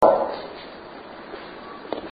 EVP Evidence
The following is a collection of audio clips we captured during our investigation at the Bellview Biltmore Resort in Clearwater, FL.
The voice in the EVP appears to be crying out "mommy."
mommy.mp3